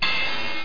clash.mp3